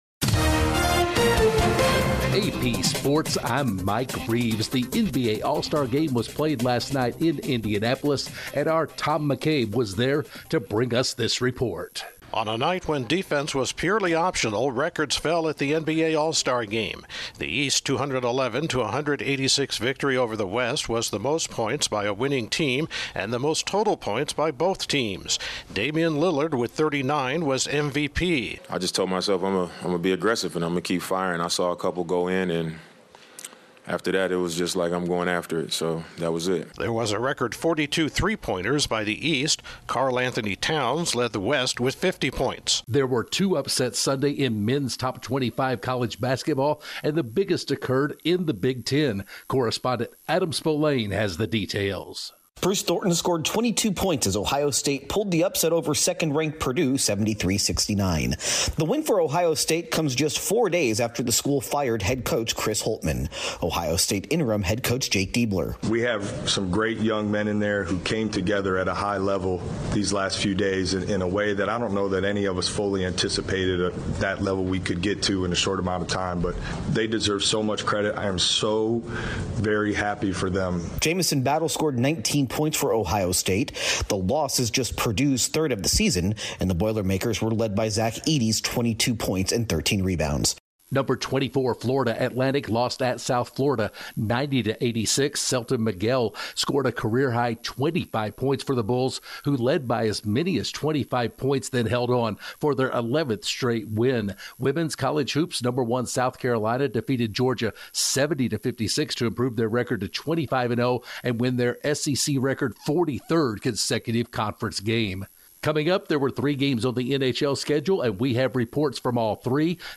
The NBA stars put on a show, a pair of men's basketball top 25 teams lose, the top ranked team in women's college basketball records a record setting victory, it was a light schedule in the NHL, the PGA Tour sees a come from behind winner, and the Daytona 500 is pushed by a day due to weather. Correspondent